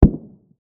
bass_large.ogg